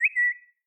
birdsong.mp3